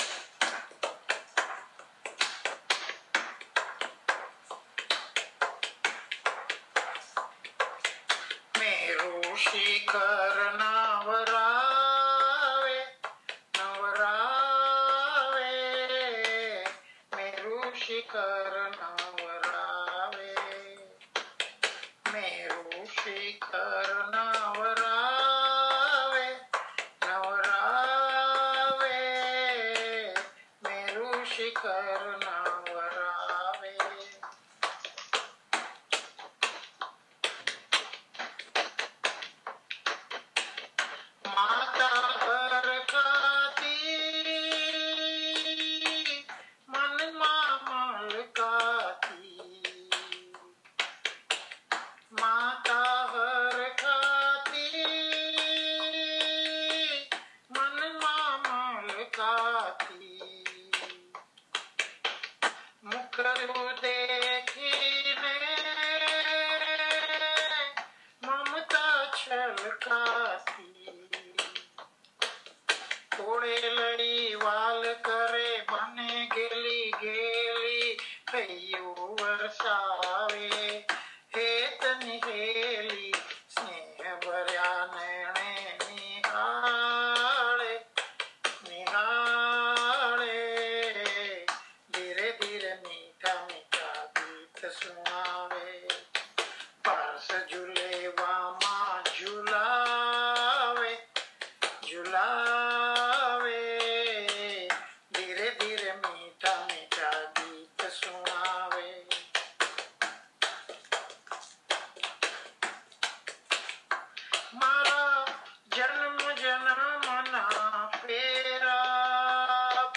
Continuous Jain Garba - Raas